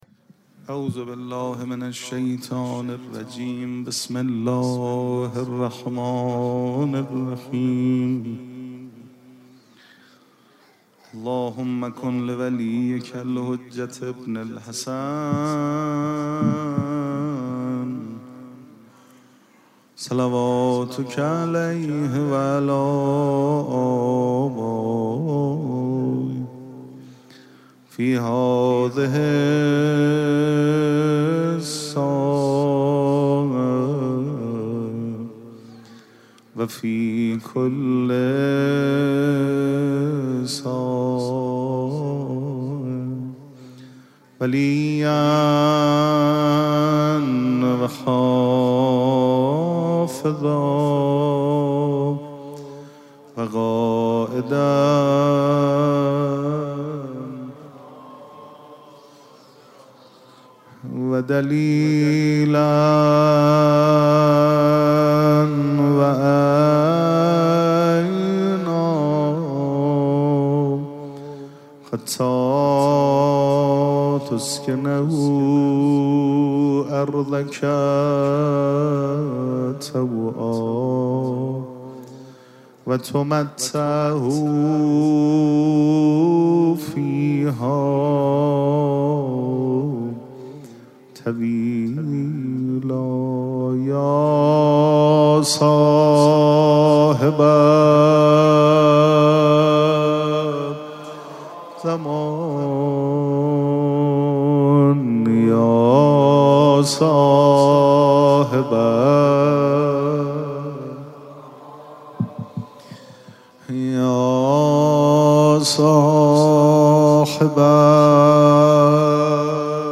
مراسم جشن ولادت حضرت صاحب الزمان (عج)
سبک اثــر پیش منبر